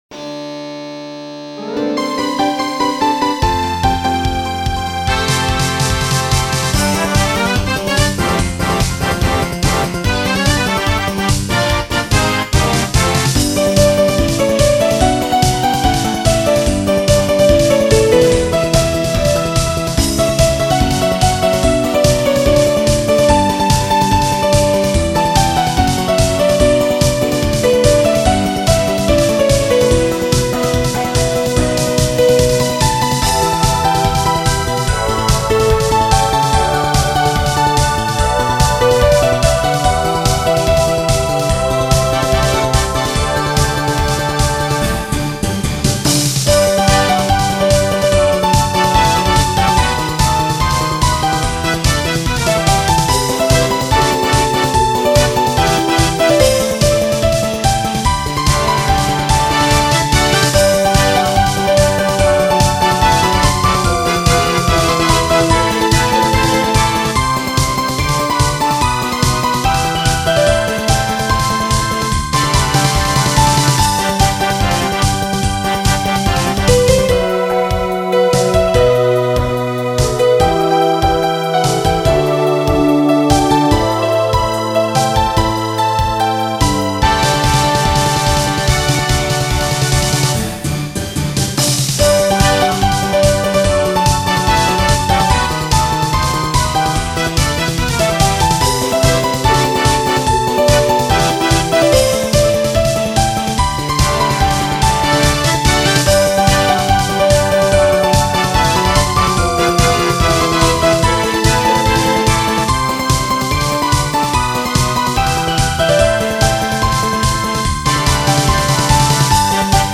これらの曲は、全てドリームキャストを使って作りました。